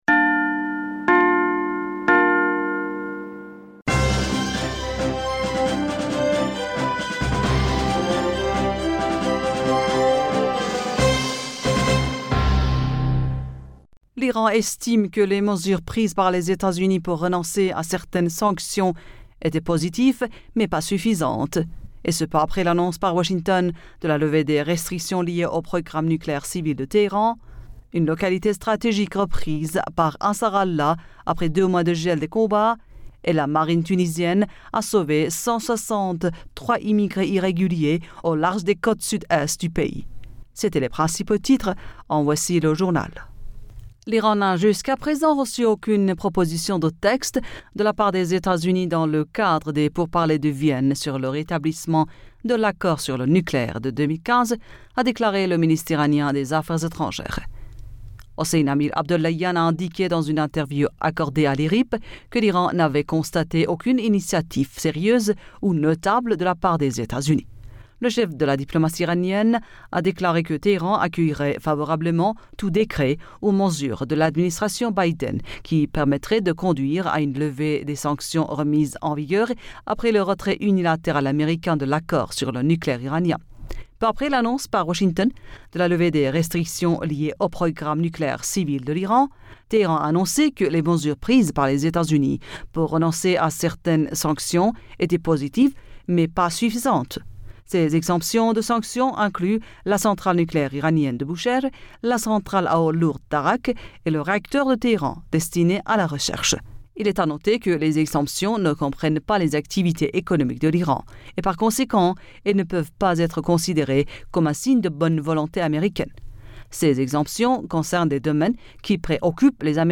Bulletin d'information Du 07 Fevrier 2022